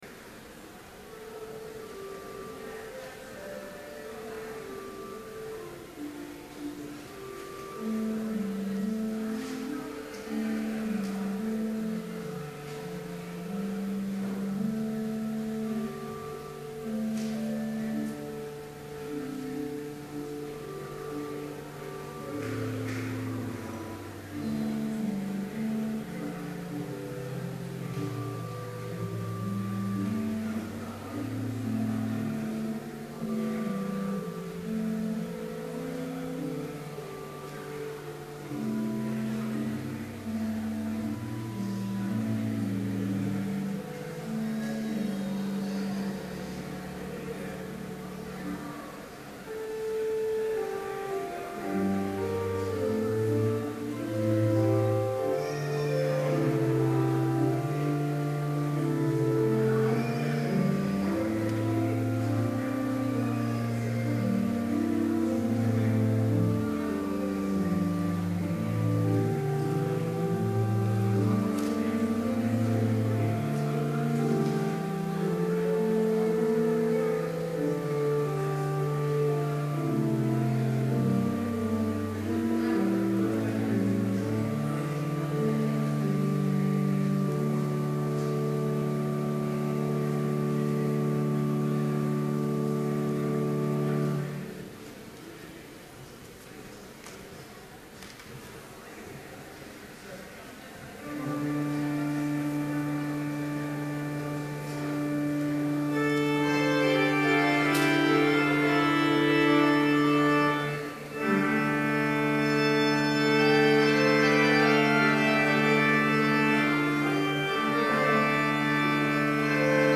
Complete service audio for Chapel - April 19, 2012